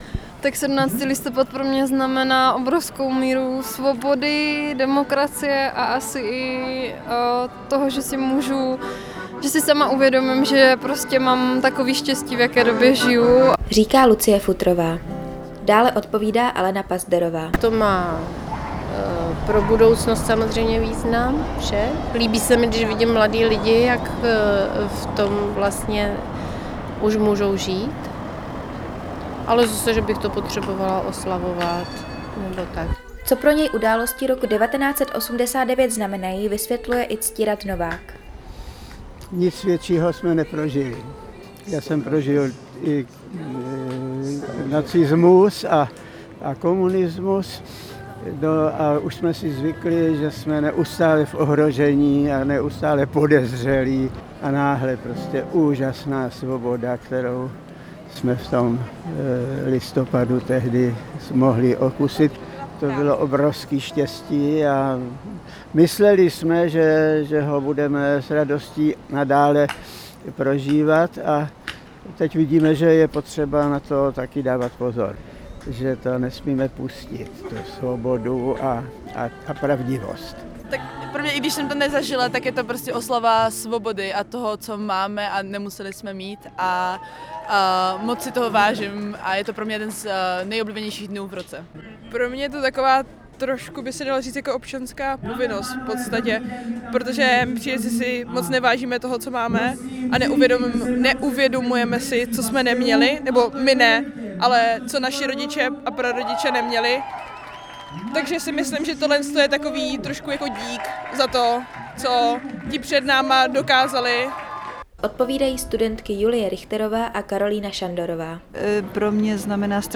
Dnešní státní svátek připomíná také události roku 1989. Jak na tento den lidé vzpomínají a jak vnímají oslavy s odstupem času jsme se ptali v ulicích Brna.
Anketa: Jak si Brňané připomínají Den boje za svobodu a demokracii?